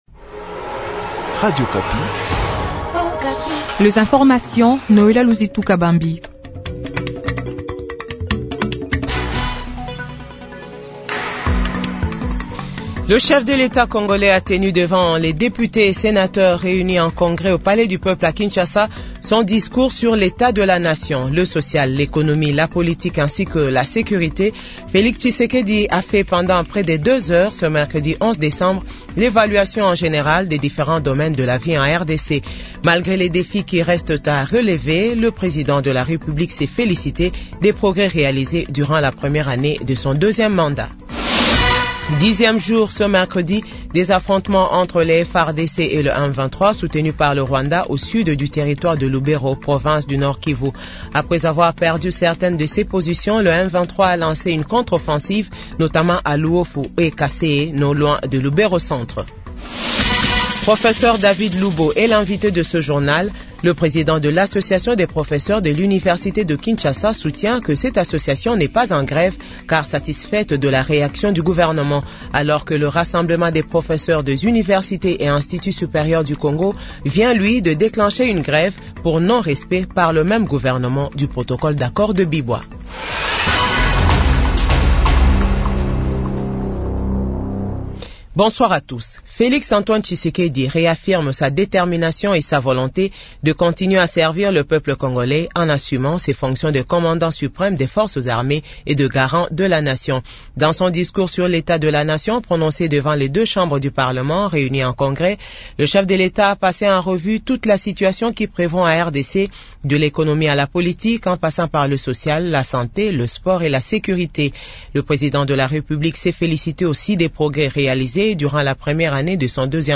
JOURNAL FRANÇAIS DE 18H00